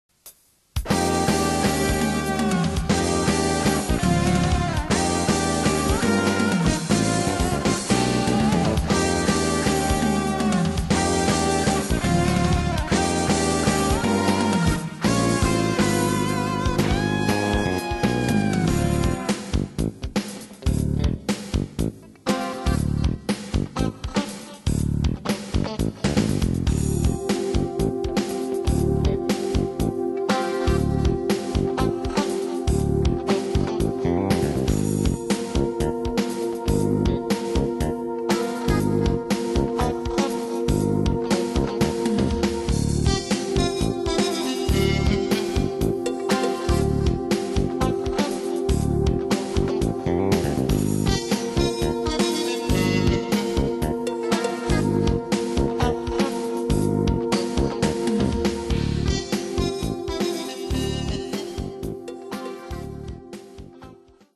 今日できた曲はギターよりキーボードを意識した曲で、少し今までとは違うイメージがあるわ。
感想　なんか懐かしい音、当時新たに手に入れたROLAND SC-55で作ったデモですね(^^o)。
曲は少しフュージョン寄りでメロはキーボードを想定したものです。後にライブの定番曲になった「ビック・クランチ」という曲です(^^o)。